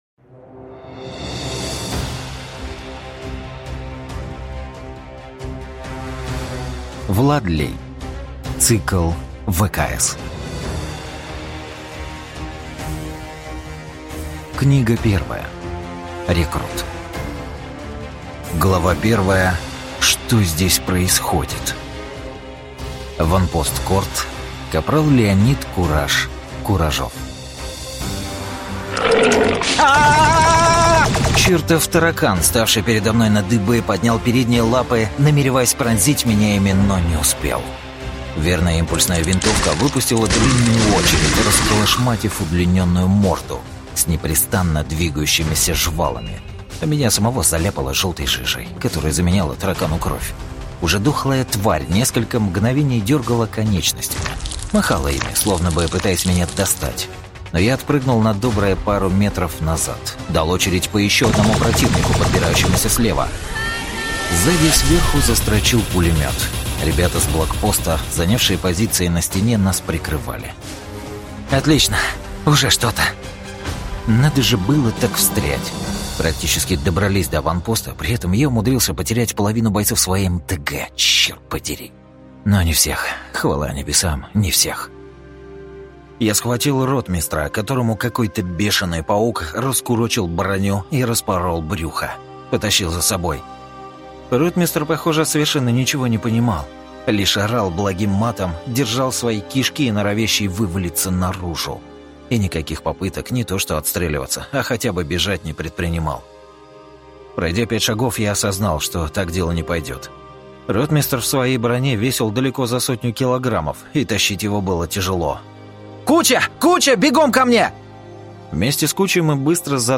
Аудиокнига «Космодесы».